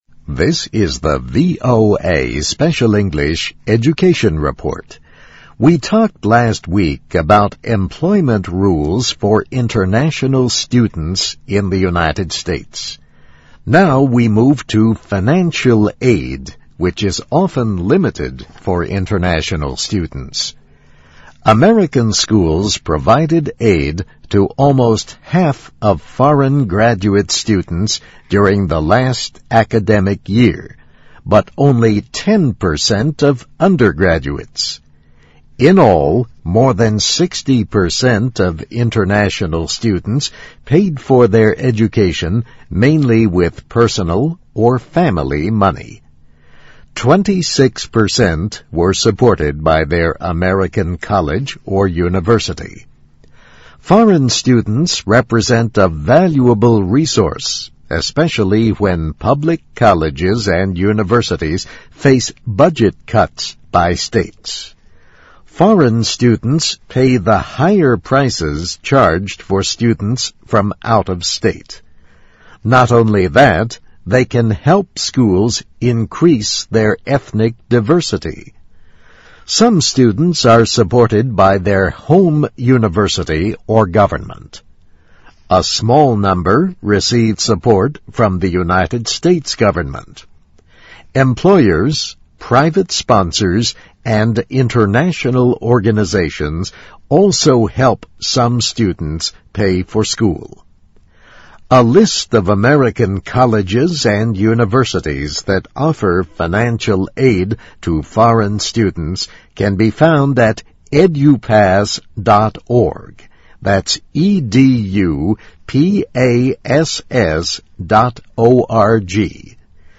VOA慢速英语2009-Education Report - Foreign Student Series: Finan 听力文件下载—在线英语听力室